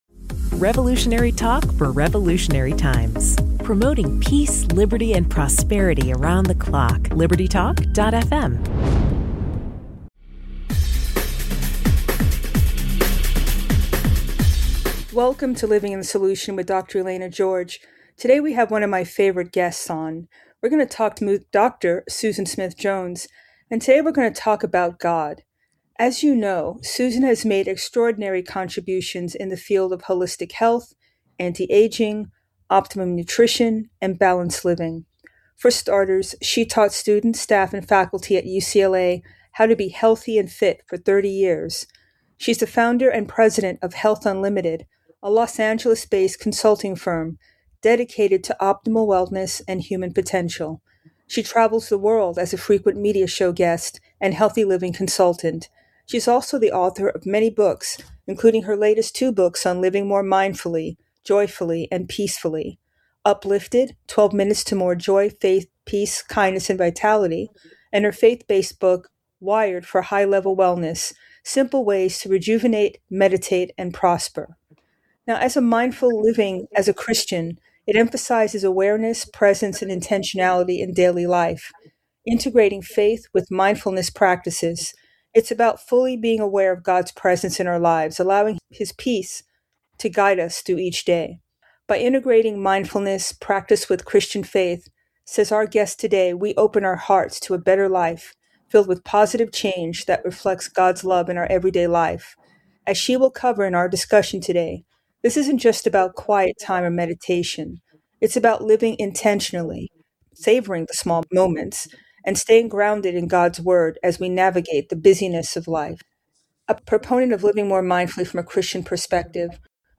Detox Interview